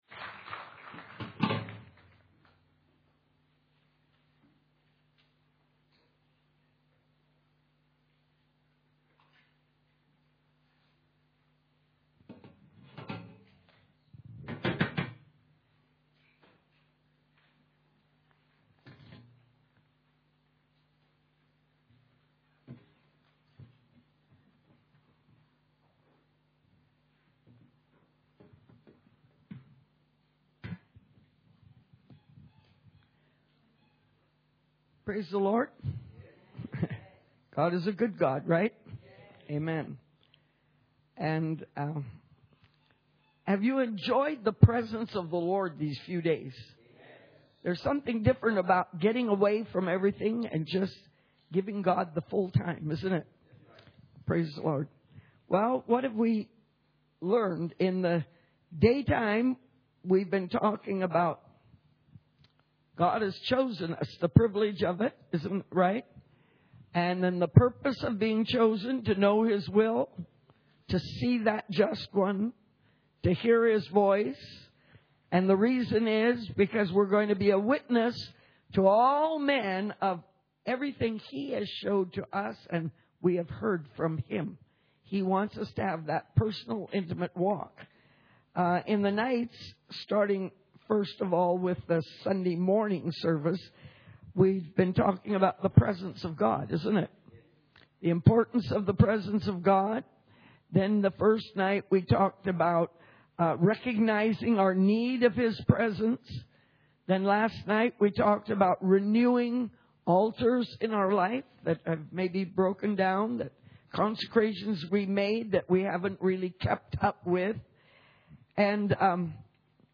Church Camp 2012 Session 6A – Take the Name of Jesus with You